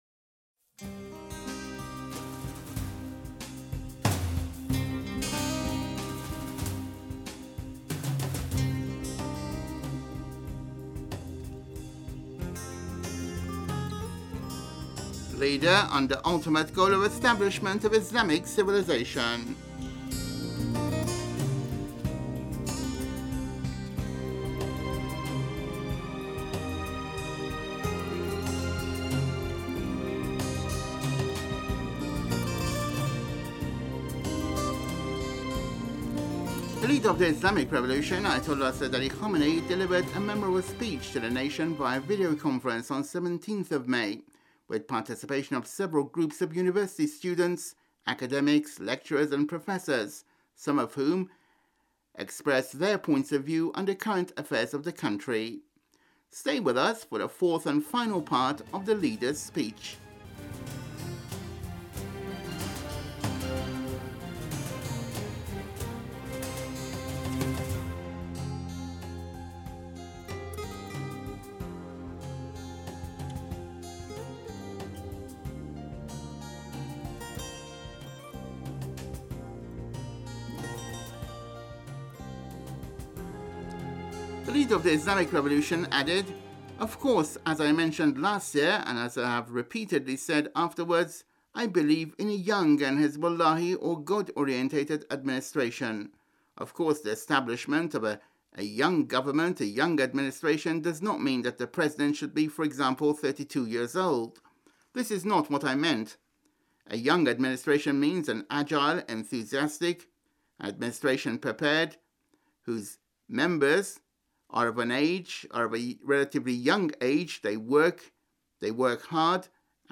Leader of the Islamic Revolution, Ayatollah Seyyed Ali Khamenei, delivered a memorable speech to the nation via videoconferencing on May 17 with the partic...